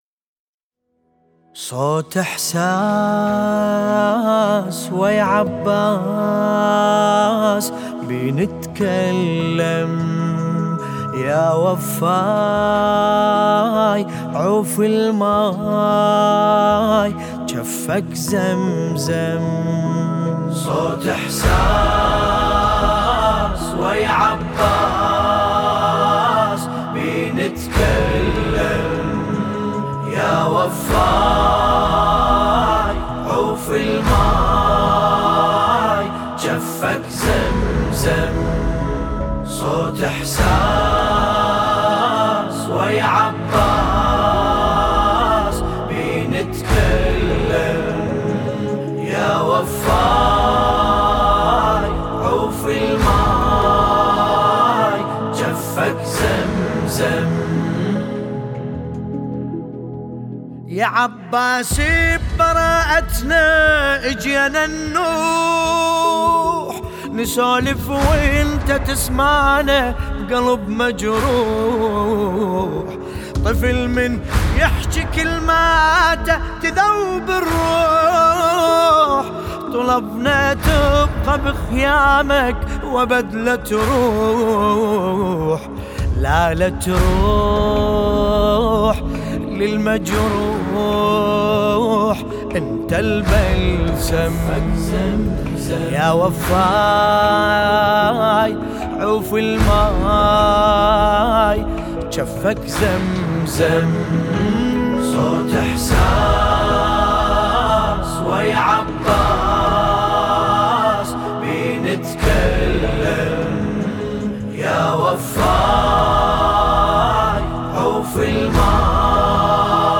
سينه زنی